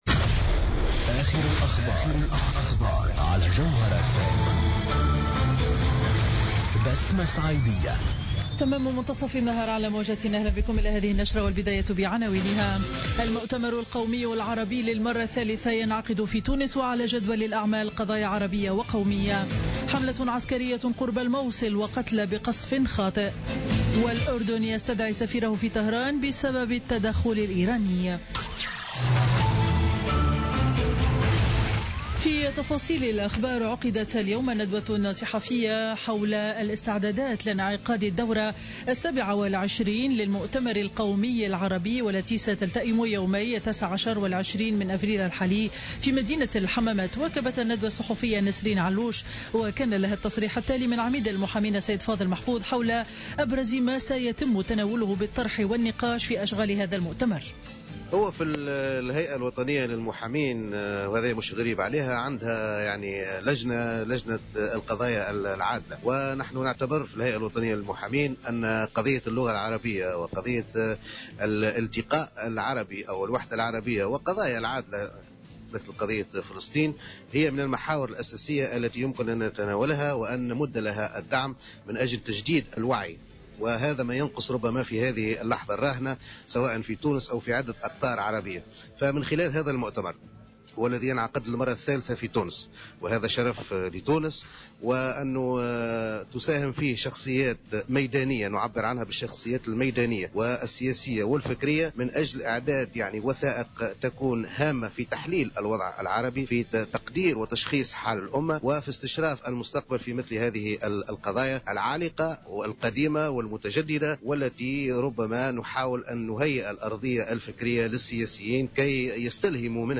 نشرة أخبار منتصف النهار ليوم الاثنين 18 أفريل 2016